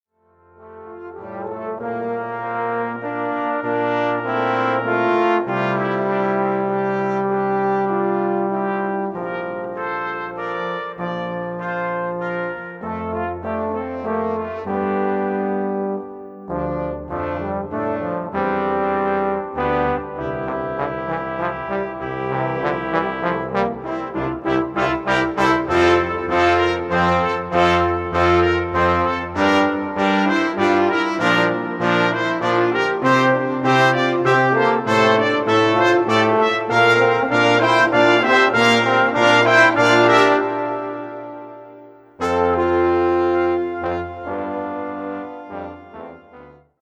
Genre: Fusion.